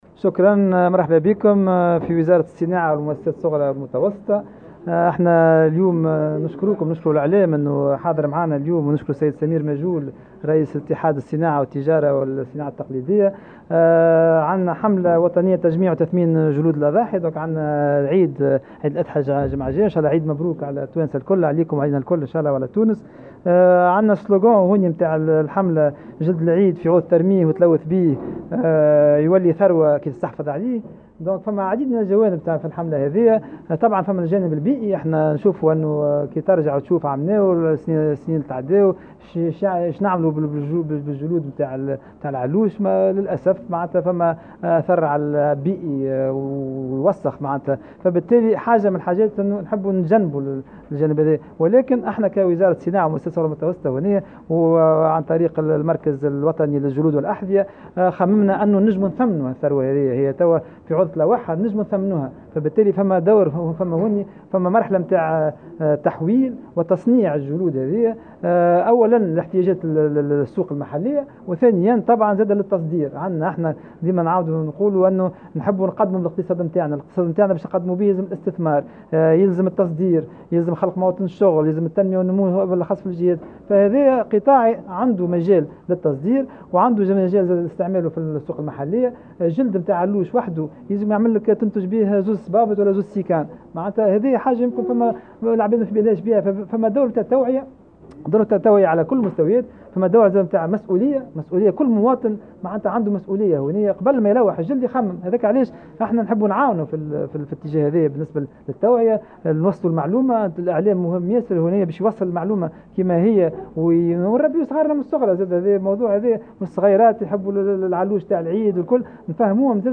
و تهدف هذه الحملة إلى تثمين جلود الأضاحي عوضا عن رميها و هذا للحفاظ على البيئة و الحد من التلوث الذي ينجر عن إلقاء جلود الأضاحي بطريقة عشوائية. و قال وزير الصناعة و المؤسسات الصغرى و المتوسطة سليم فرياني اليوم في تصريح لمراسلة الجوهرة 'اف ام' إنّ جلود الأضاحي تعدّ ثروة إذا وقع تحويلها و إعادة تصنيعها أولا لتغطية حاجيات السوق المحلية و ثانيا للتصدير.